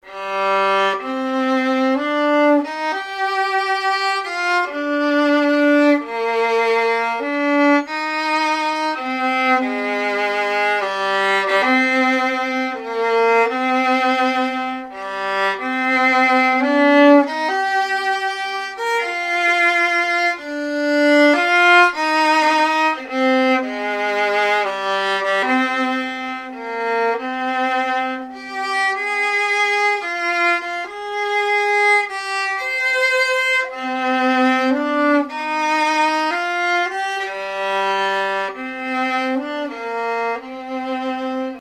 New Violin (very rough)